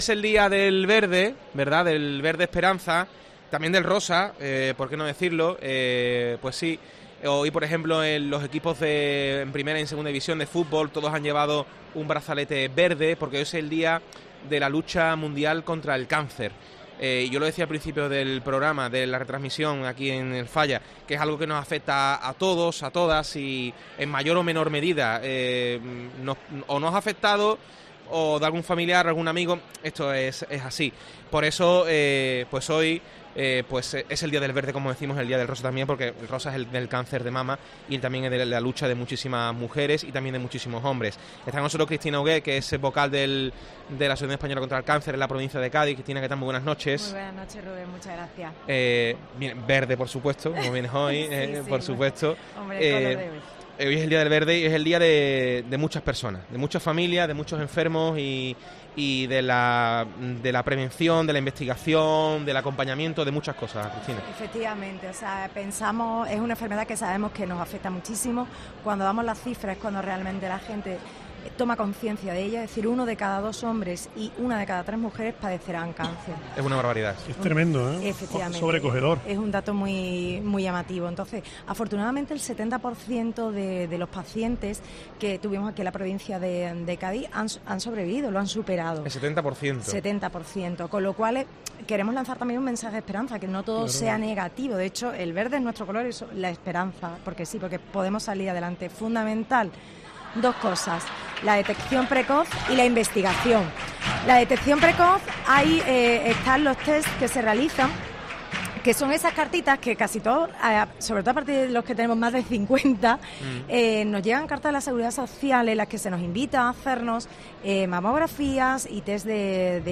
desde el Gran Teatro Falla